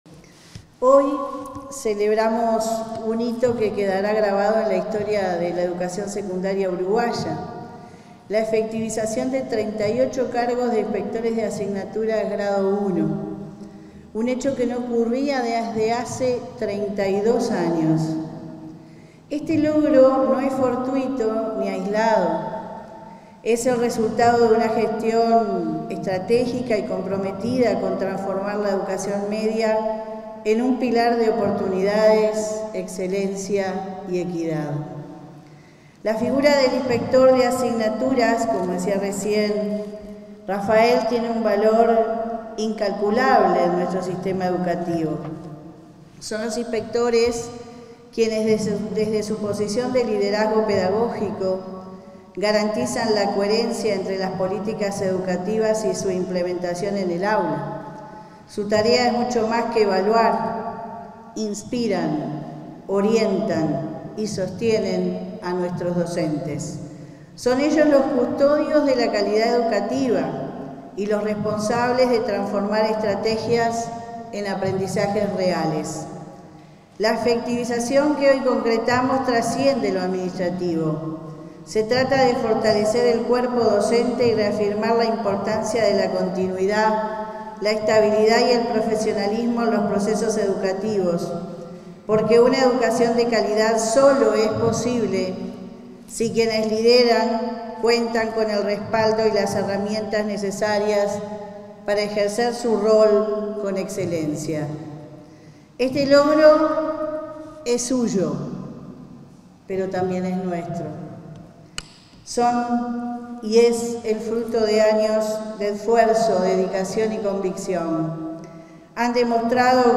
Palabras de la directora general de Educación Secundaria de ANEP, Jenifer Cherro
Palabras de la directora general de Educación Secundaria de ANEP, Jenifer Cherro 23/12/2024 Compartir Facebook X Copiar enlace WhatsApp LinkedIn La Administración Nacional de Educación Pública (ANEP) realizó, a través de la Dirección General de Educación Secundaria (DGES), este 23 de diciembre, la ceremonia de efectivización de inspectores de asignaturas de todo el país, que asumirán sus cargos a partir del 1.° de marzo de 2025. En el evento, disertó la titular de la DGES, Jenifer Cherro.